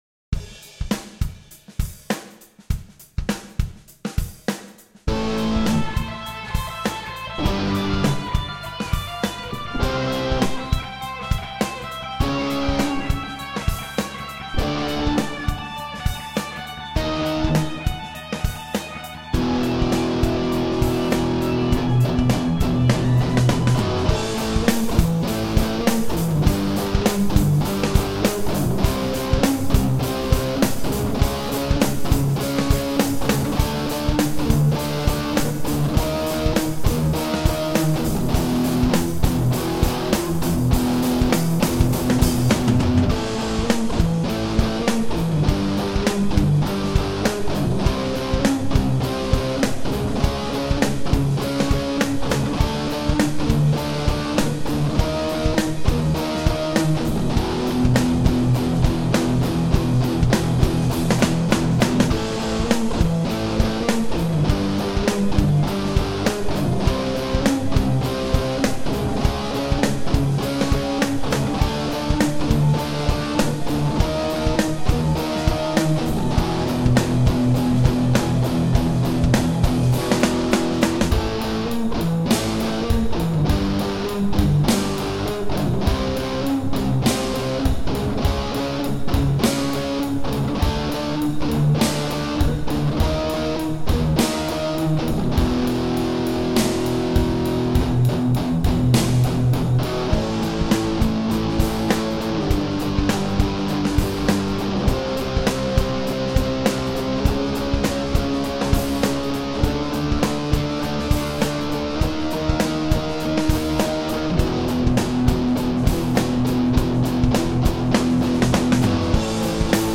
Track 1 - Am
• The 1st track is quite heavy and require paying attention to the chord progression, here it is:
Am .. G7 .. C .. C#dim .. Dm .. D#dim .. E7 .. E7
Style: Neoclassic, Heavy Metal
neoclassic_groove.ogg